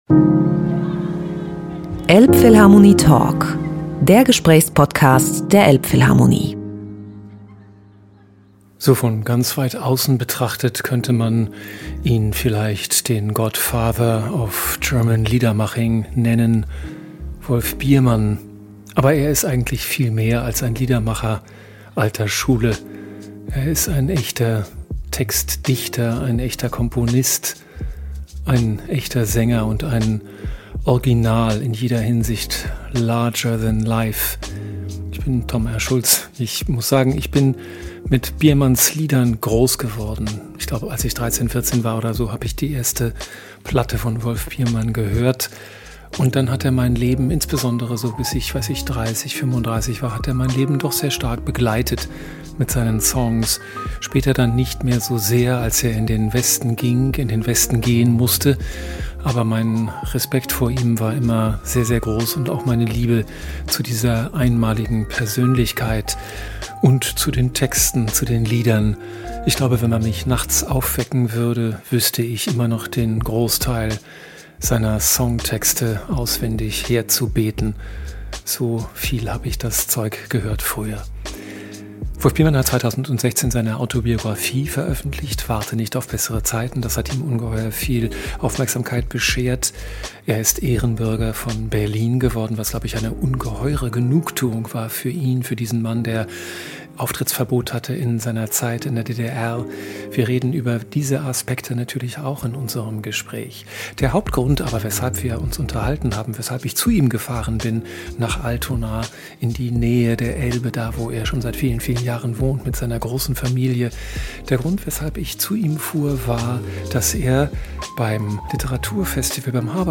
In diesem Podcast spricht Biermann über seine eigene Geschichte, seinen Abend in der Elbphilharmonie, unterhält sich mit Heine und singt zwischendurch auch noch.